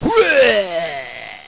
Puke.wav